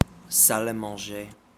Ääntäminen
États-Unis: IPA: /sa.l‿a mɑ̃.ʒe/